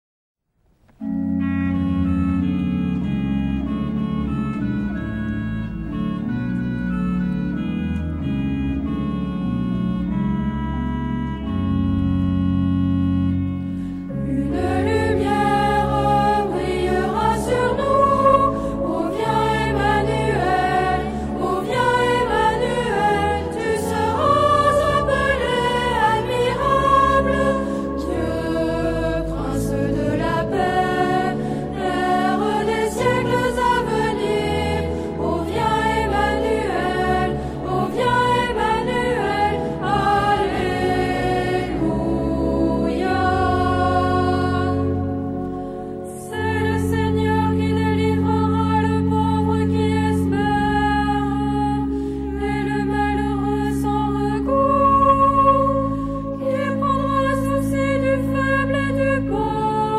Genre-Style-Form: Sacred ; Canticle
Mood of the piece: lively
Type of Choir: unisson
Instruments: Organ (1)
Tonality: F sharp minor